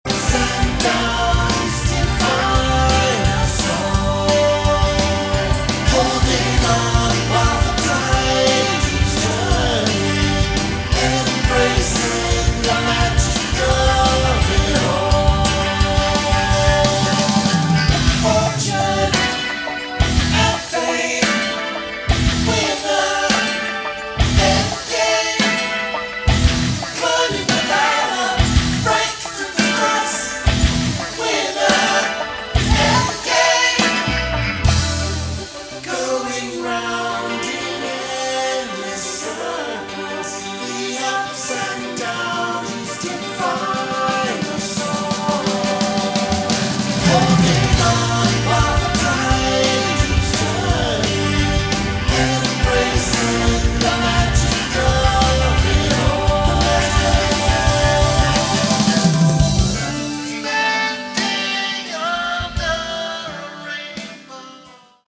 Category: Prog Rock
Bass, vocals
Drums
Guitars
Keyboards